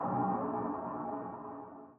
sonarTailAirMediumShuttle2.ogg